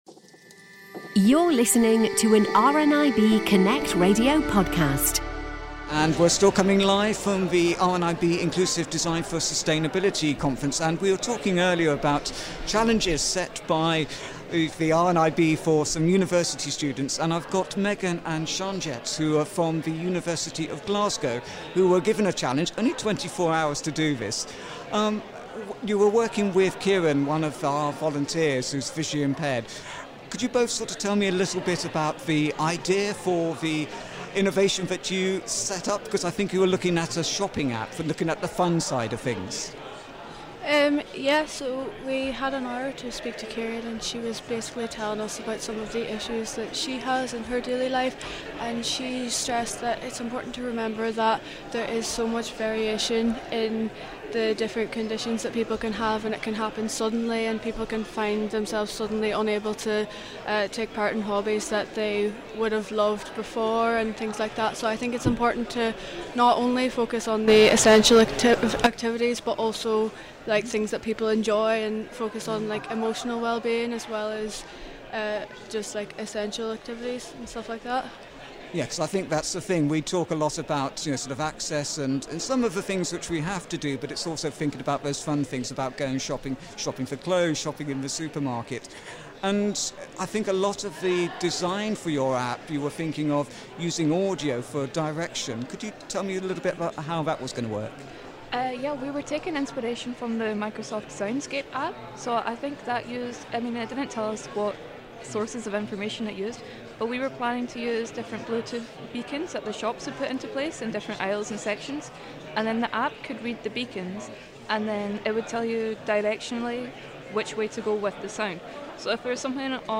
On Tuesday 18th of March, The Lunch Break was broadcasting live from the Inclusive Design for Sustainability Conference in Glasgow.